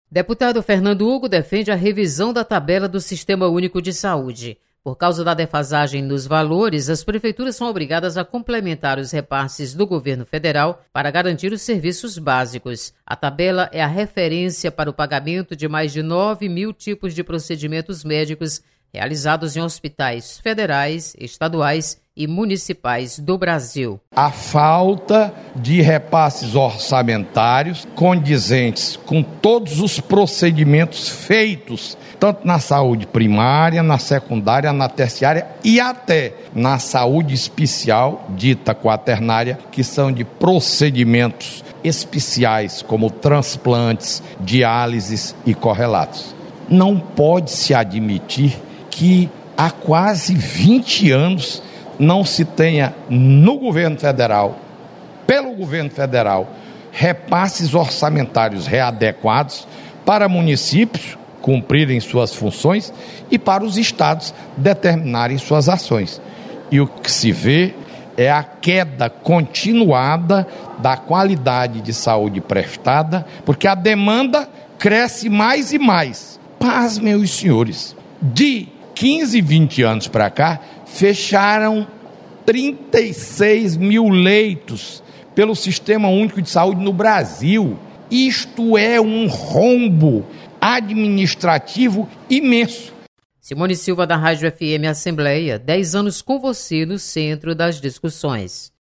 Deputado Fernando Hugo defende revisão da tabela do SUS.